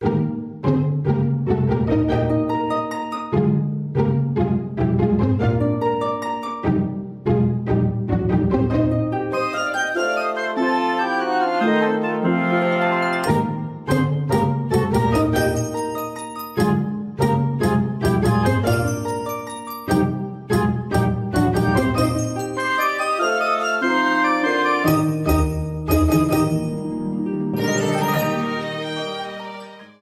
Faded in the end
Fair use music sample